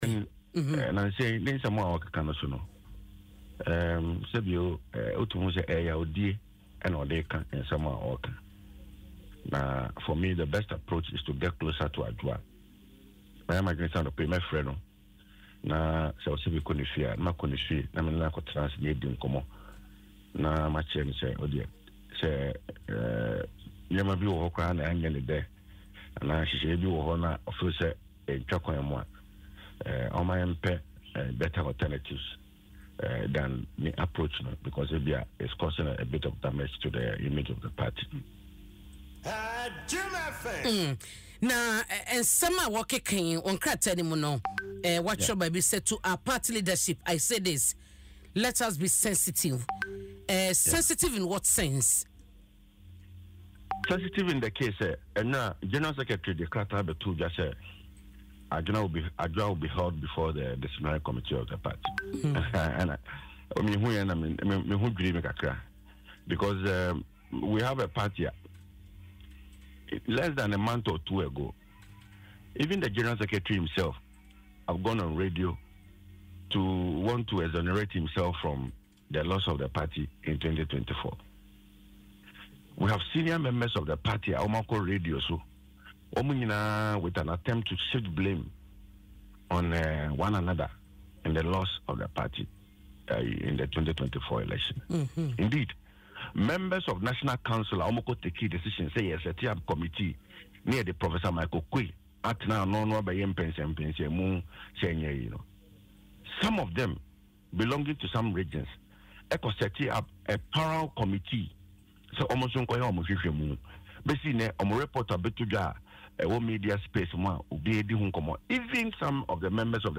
Speaking on Adom FM’s Dwaso Nsem, OPK cautioned the NPP leadership against creating the impression of targeting specific individuals.